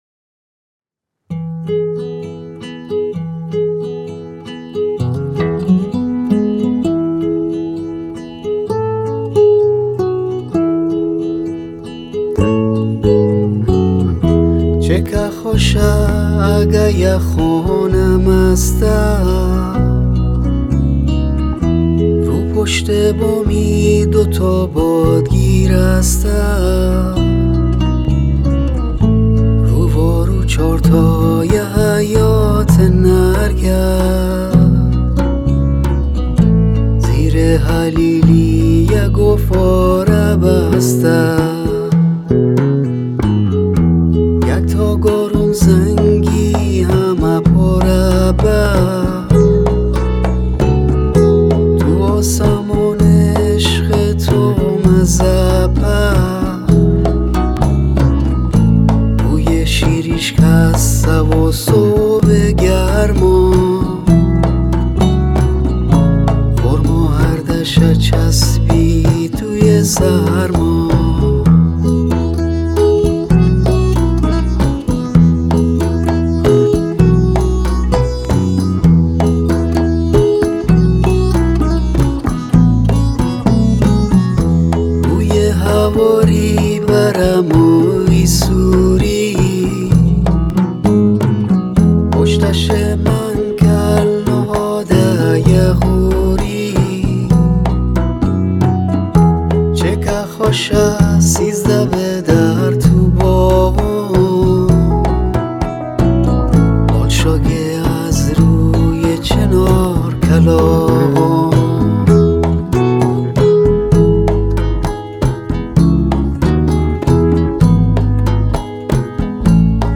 روایت آکوستیک